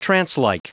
Prononciation du mot trancelike en anglais (fichier audio)
Prononciation du mot : trancelike